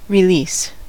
release: Wikimedia Commons US English Pronunciations
En-us-release.WAV